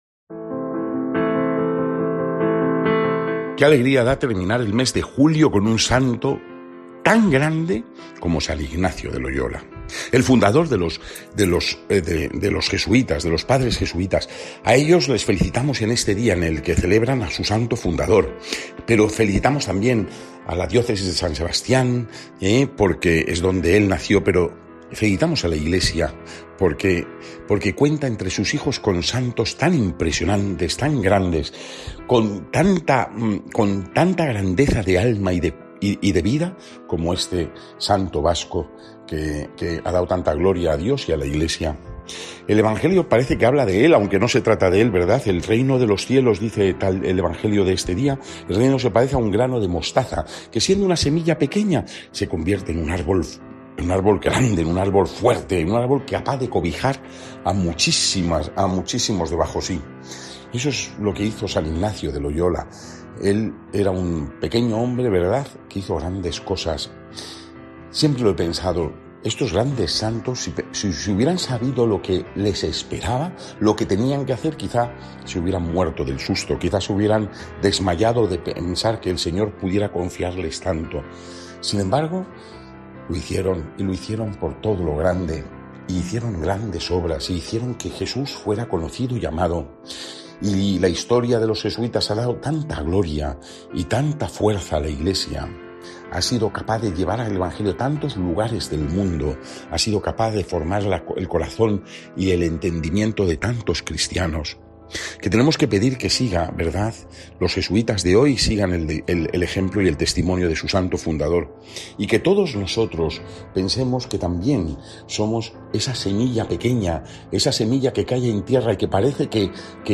Lectura del santo evangelio según san Mateo 13, 31-35